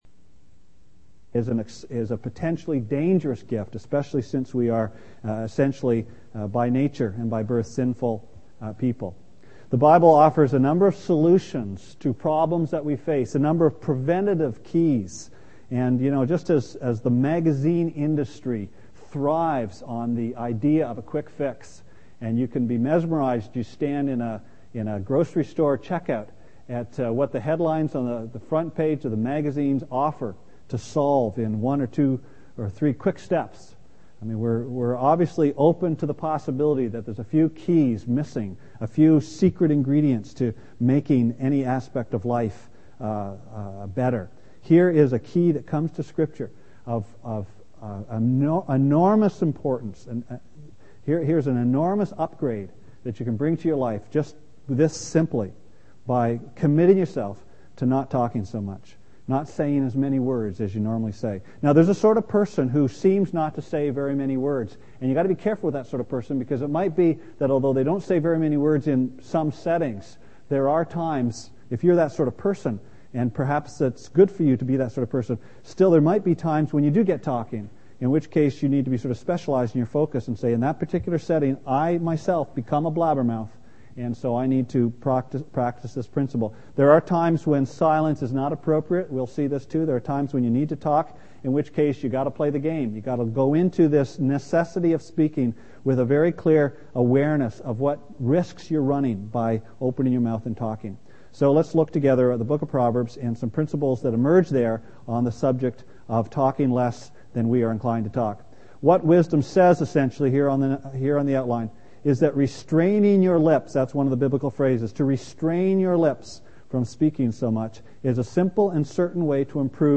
Part 4 of a Sunday evening series on Proverbs.